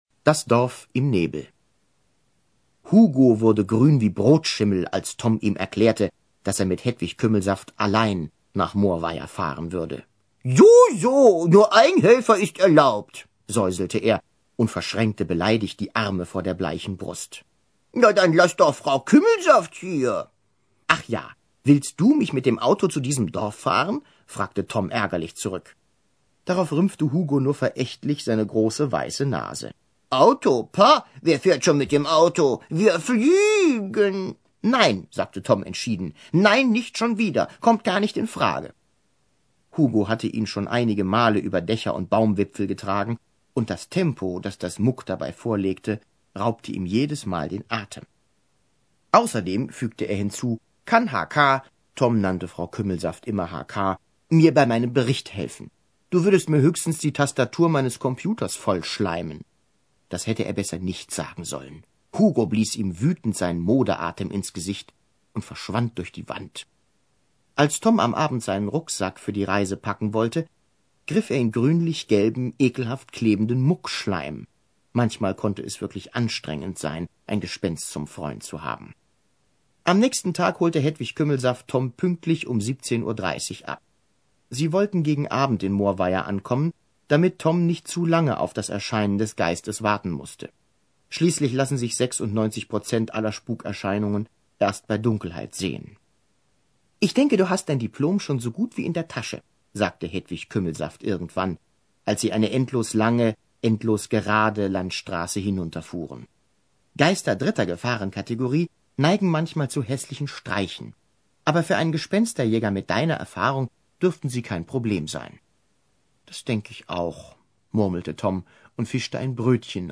tiptoi® Hörbücher ► Ravensburger Online-Shop
Gespensterjaeger_in_großer_Gefahr-Hoerprobe.mp3